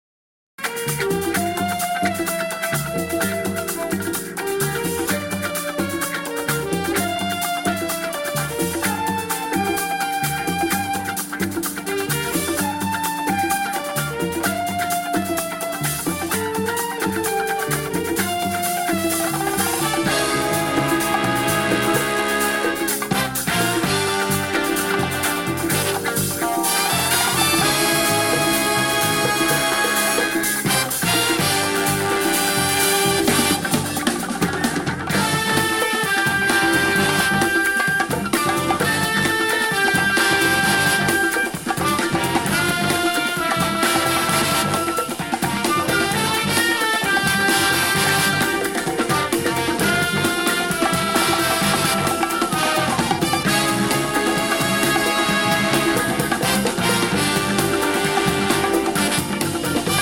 Killer covers of 2 important sound track moments.